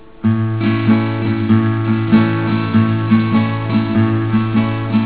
Today's strum I call a Pick strum
This strum is basically the same as the previous one except that your thumb picks a single string instead of strumming.